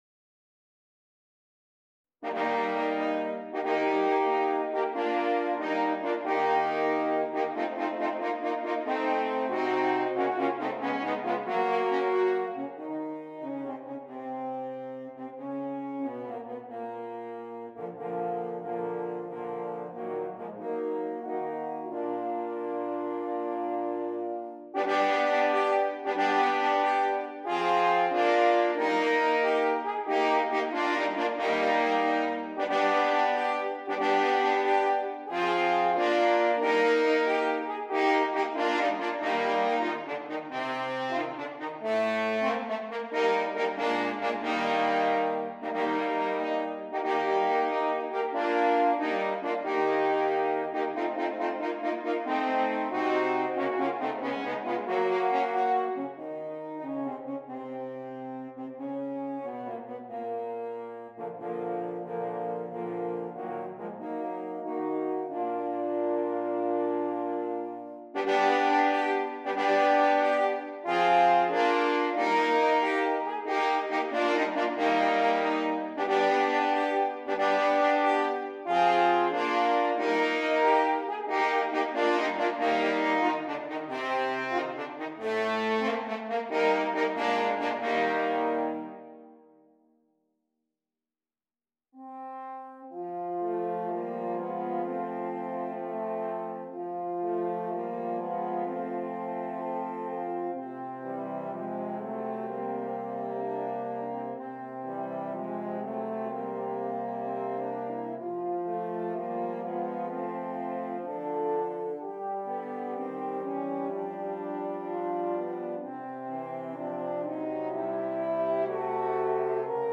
4 F Horns